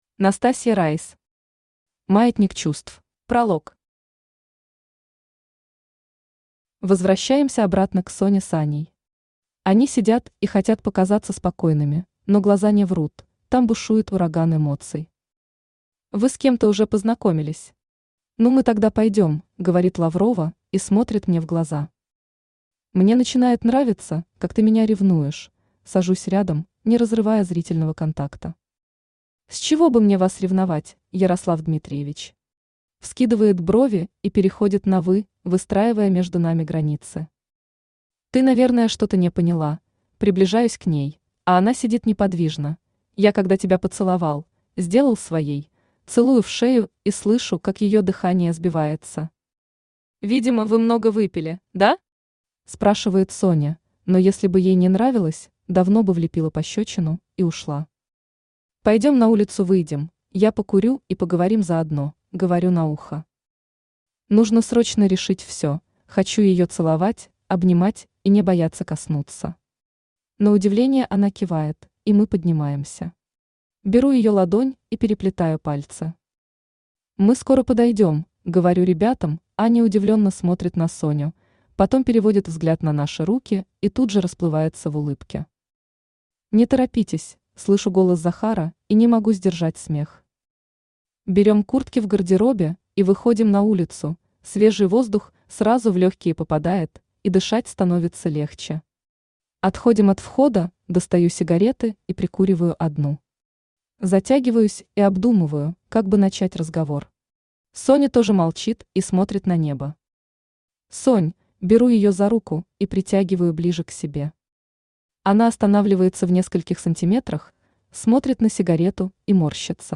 Аудиокнига Маятник чувств | Библиотека аудиокниг
Aудиокнига Маятник чувств Автор Настасья Райс Читает аудиокнигу Авточтец ЛитРес.